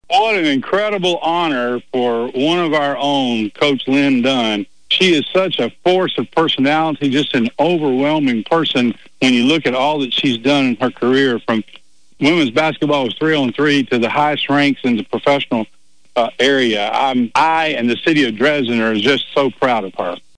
Dresden Mayor Mark Maddox expressed the pride the city has for Ms. Dunn